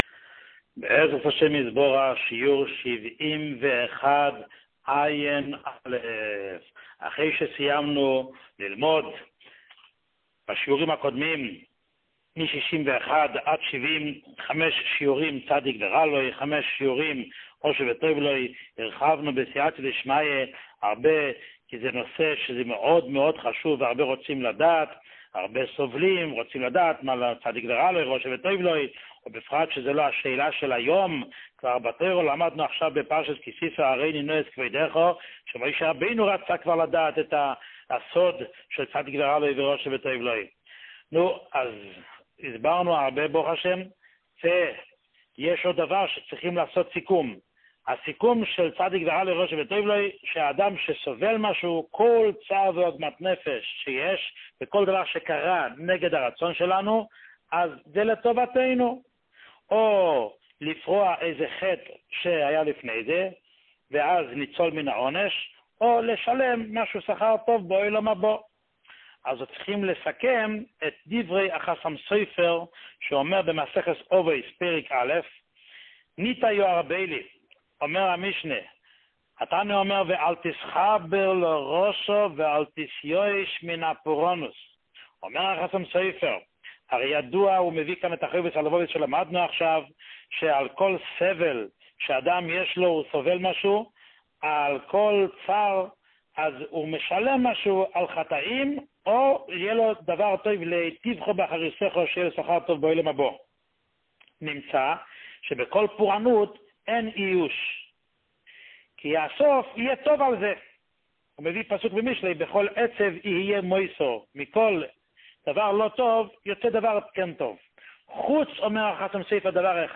שיעור 71